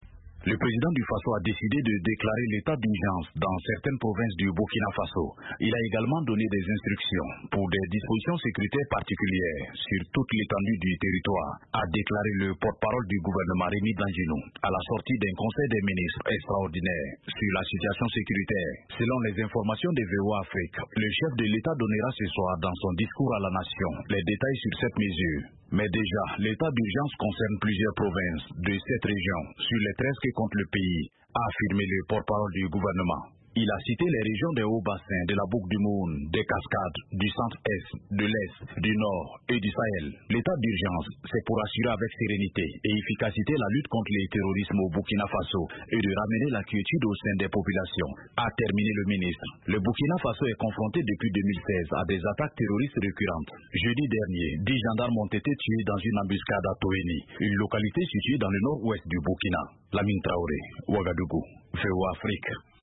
Les autorités du Burkina Faso comptent décréter "l'état d'urgence" dans plusieurs provinces du pays, en proie à des attaques jihadistes récurrentes. Rien que cette semaine, une dizaine de gendarmes ont été tués dans des attaques vers la frontière avec le Mali. De Ouagadougou, la correspondance